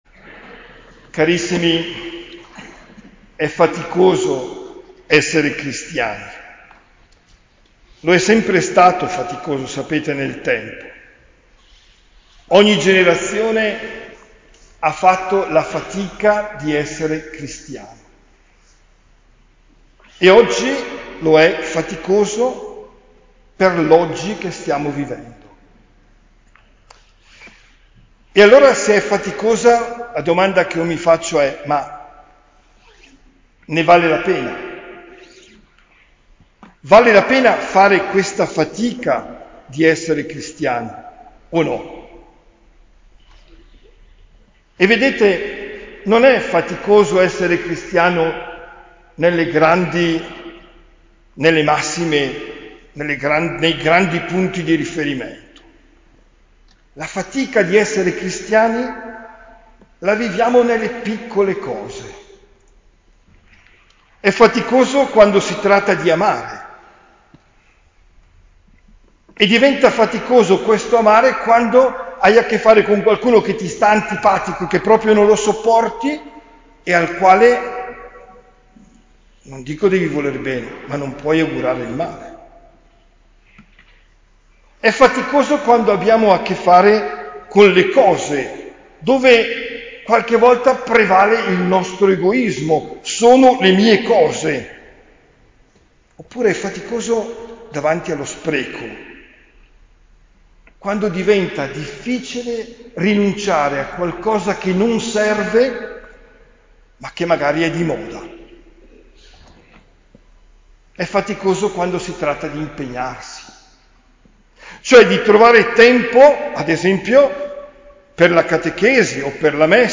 OMELIA DEL 29 GENNAIO 2023